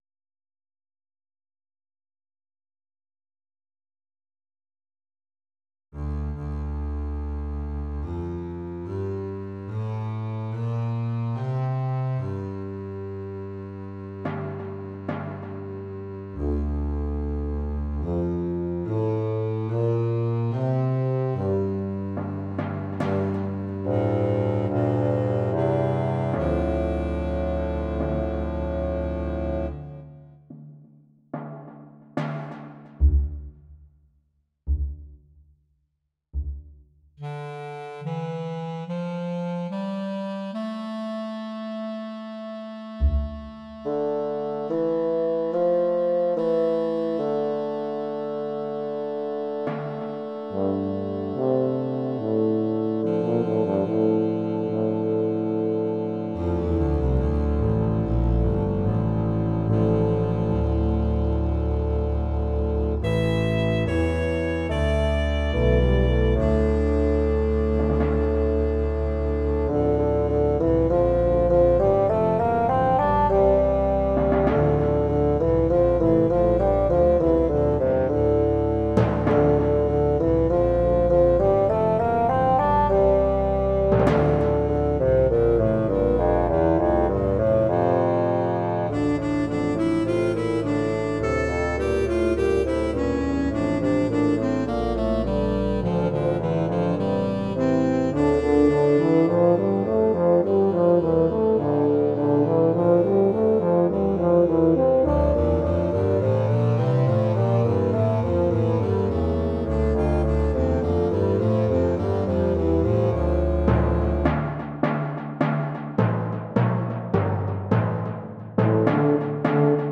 音源は全てVSC-88です。
自分でも記憶にない古いMIDIファイルがまた出てきたので載せておきます(^^;低音楽器五つという不思議な曲です。